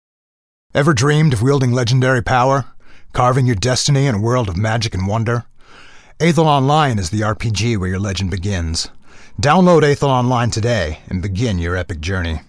Male
Radio Commercials
Words that describe my voice are Warm, natural, enaging.